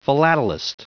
Prononciation du mot philatelist en anglais (fichier audio)
Prononciation du mot : philatelist